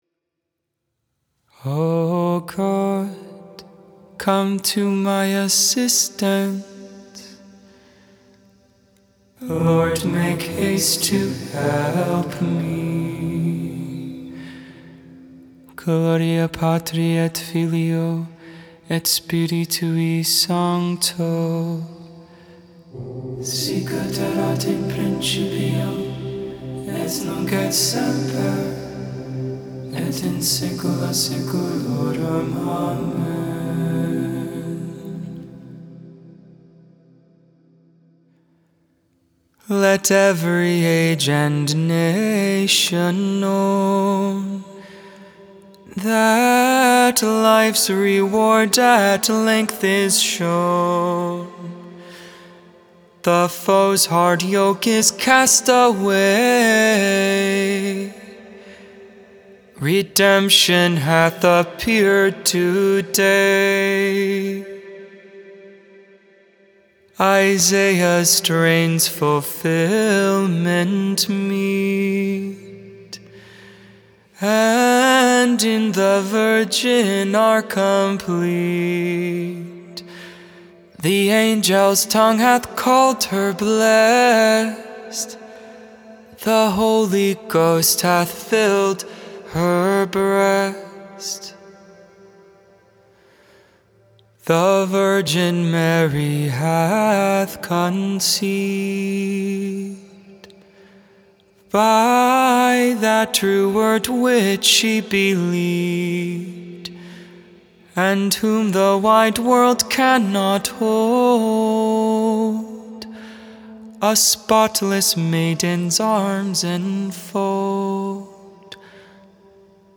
3.24.22 Vespers, Thursday Evening Prayer
(VespersII Antiphon) Magnificat (English, Tone 8) Intercessions: Lord, fill our hearts with your love.